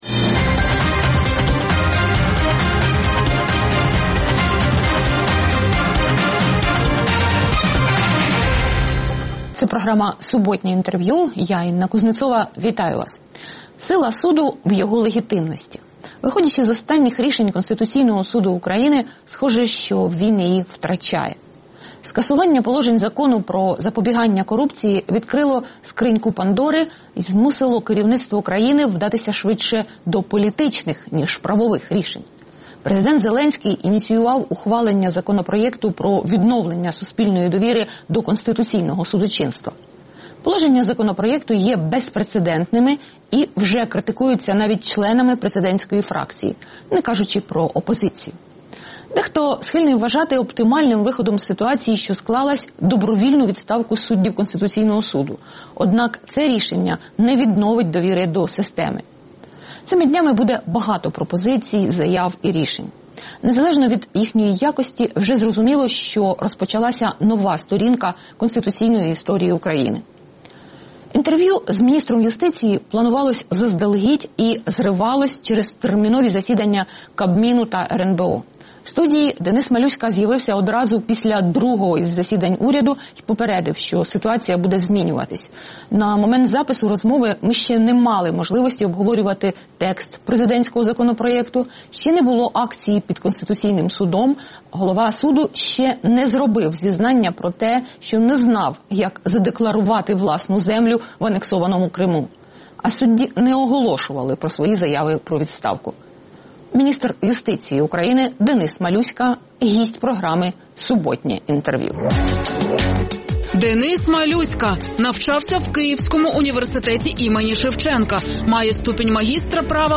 Суботнє інтерв’ю | Денис Малюська, міністр юстиції України
Суботнє інтвер’ю - розмова про актуальні проблеми тижня. Гість відповідає, в першу чергу, на запитання друзів Радіо Свобода у Фейсбуці